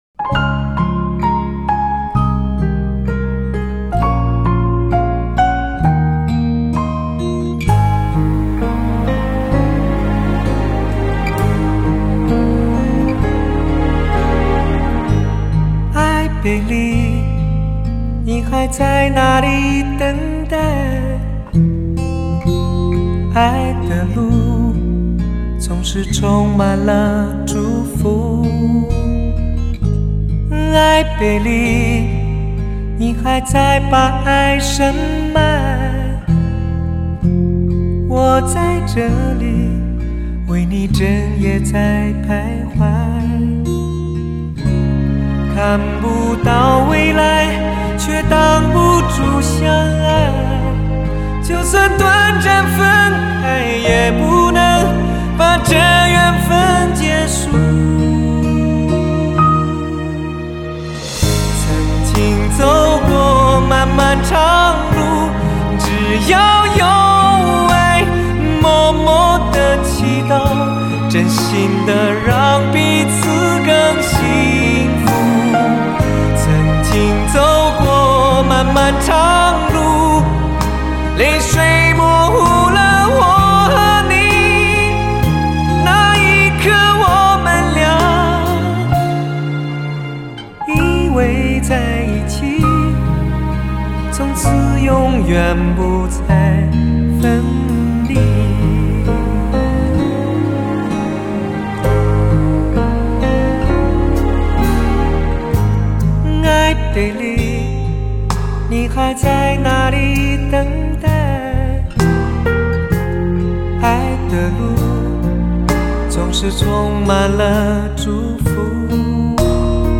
呵呵....还是男声有味道~
音效很好